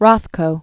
(rŏthkō), Mark Originally Marcus Rothkowitz. 1903-1970.